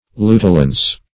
Lutulence \Lu"tu*lence\, n.